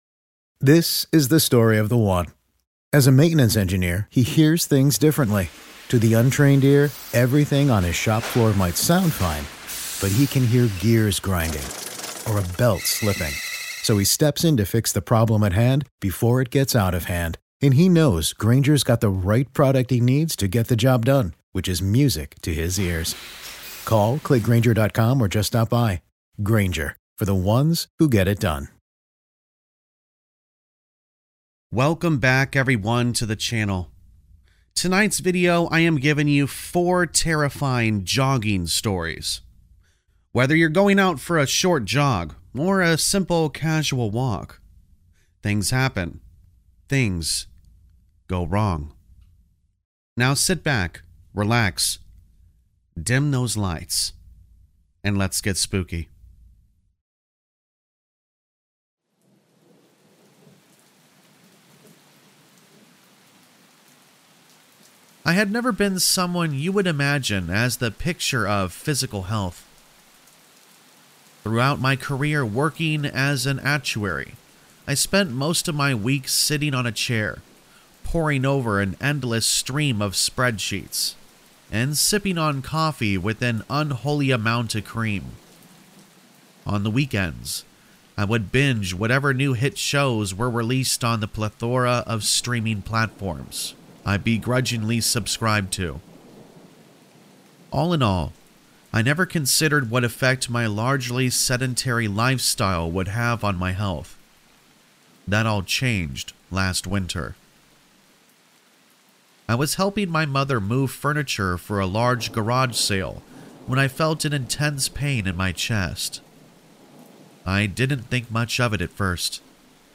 4 TERRIFYING Jogging Horror Stories To Help You Sleep | Black Screen For Sleep | Ambient Rain Sounds